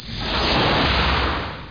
AIRGUSH1.mp3